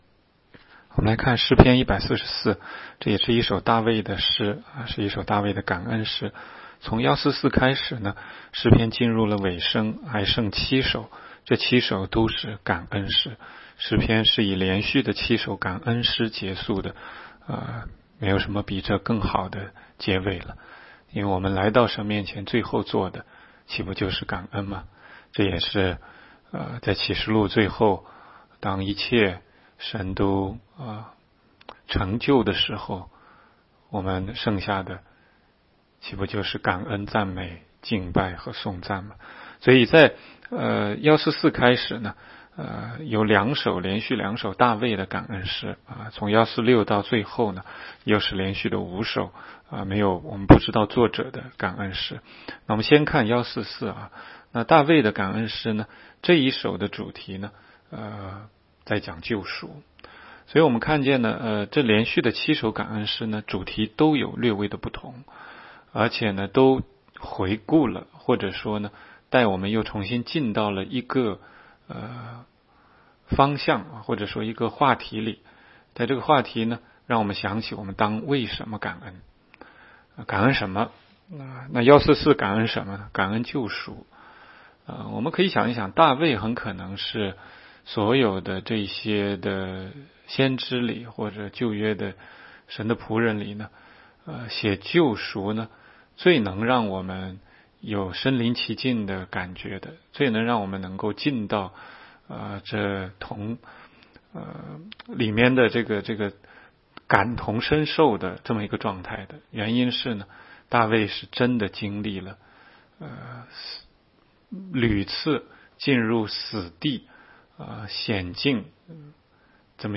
16街讲道录音 - 每日读经 -《 诗篇》144章